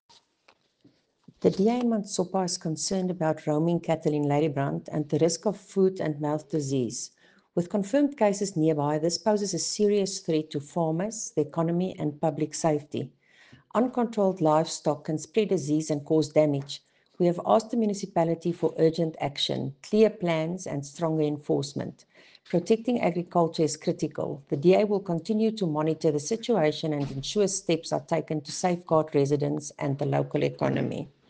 Afrikaans soundbites by Cllr Erica Moir and